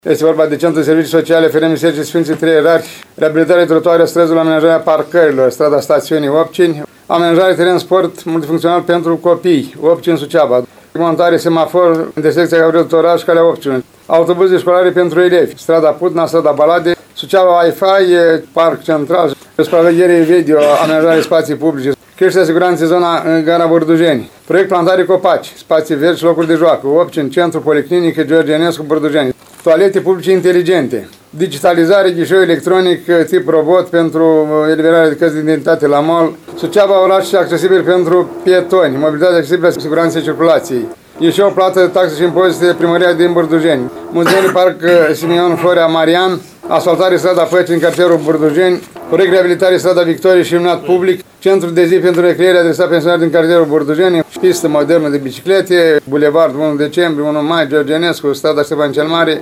Primarul ION LUNGU a detaliat cele 17 proiecte, precizând că variantele câștigătoare vor fi cunoscute pe 15 decembrie.